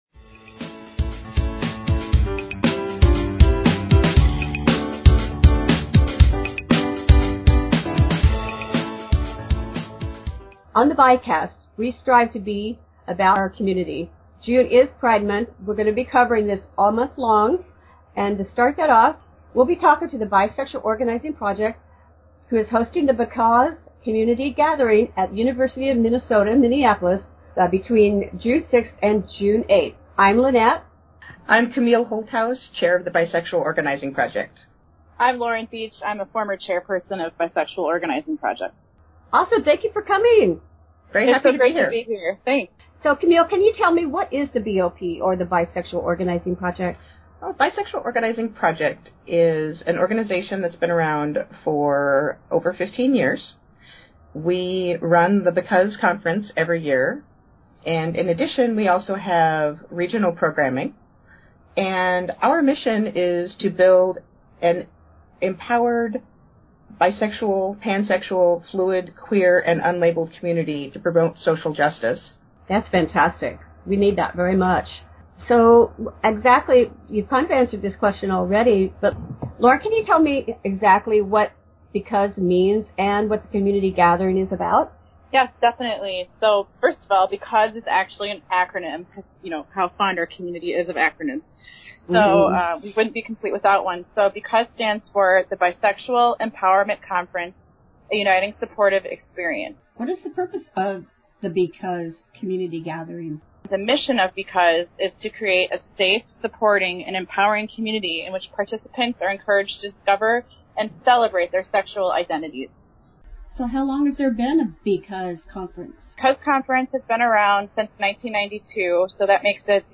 BOP Interview for BECAUSE Community Gathering – The BiCast